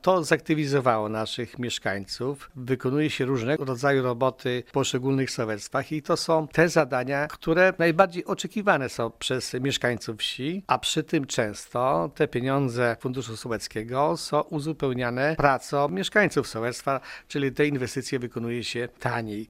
Według zastępcy wójta Wiktora Osika decyzja o wydzieleniu takich środków z budżetu gminy była słuszna: